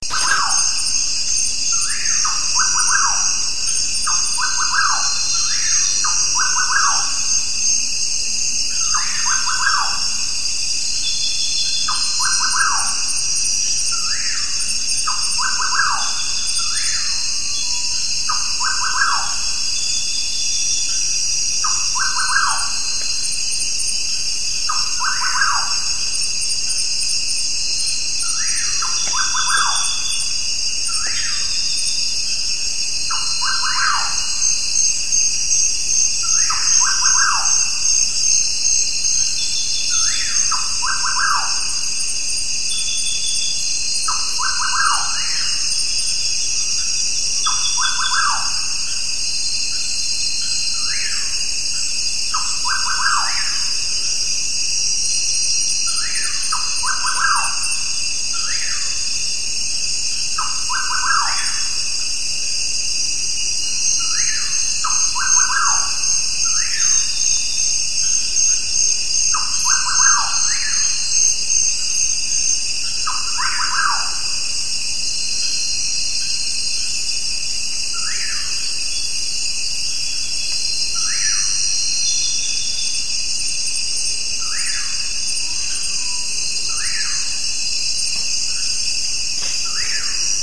night_jungle.ogg